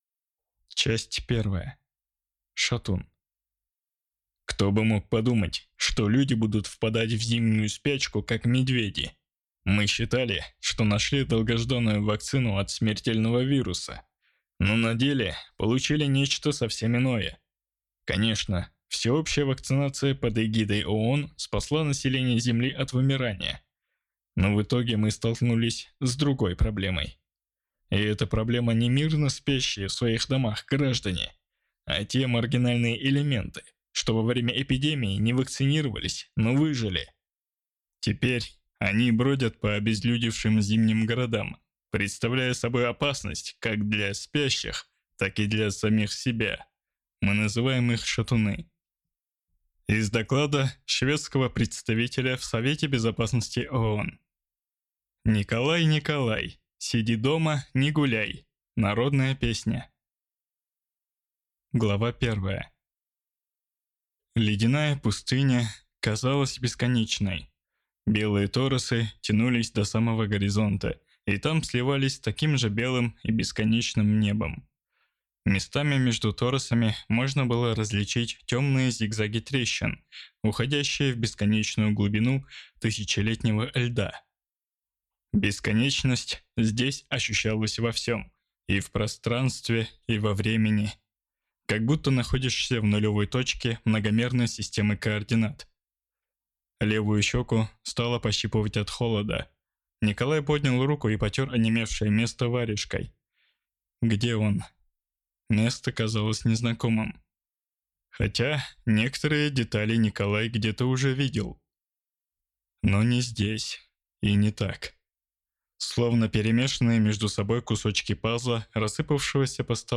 Аудиокнига Шатун. Дитя без глазу | Библиотека аудиокниг